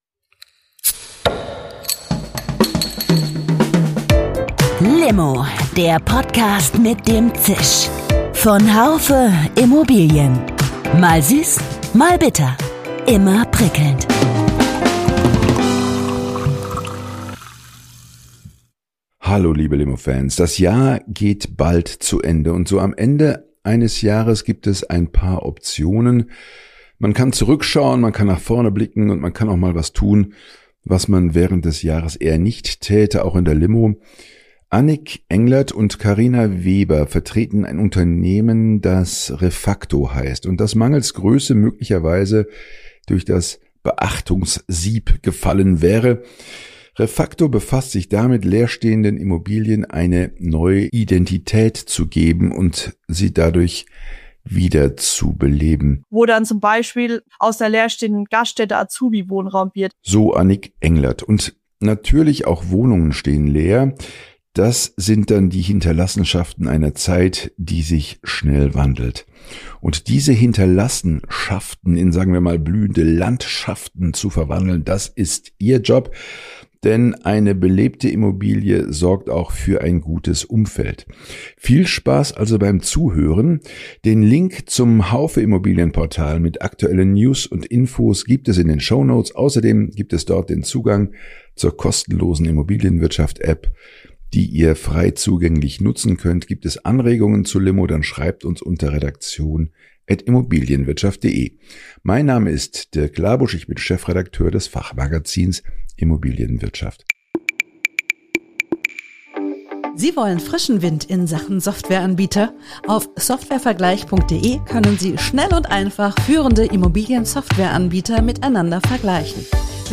Ein Gespräch über den schmalen Grad zwischen Kreativität und Wirtschaftlichkeit.